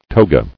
[to·ga]